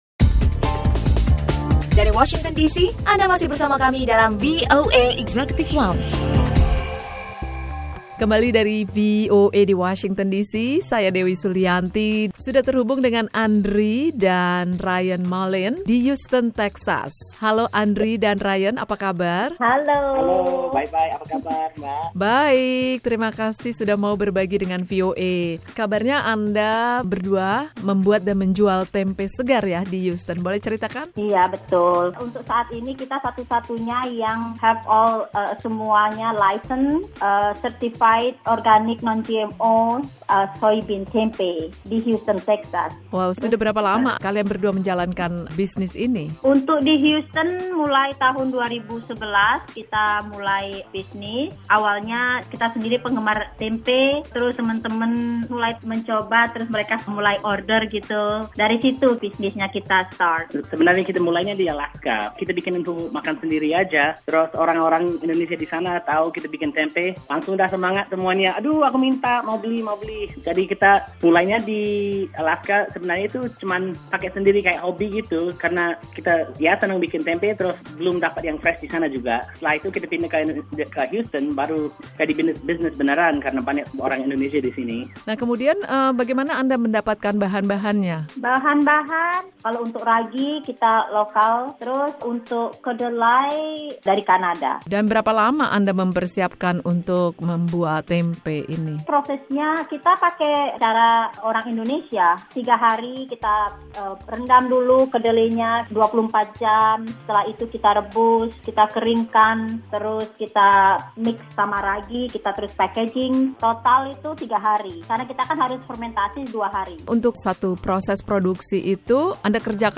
Obrolan bersama pengusaha tempe bersertifikat organik di negara bagian Texas seputar perjalanan usahanya.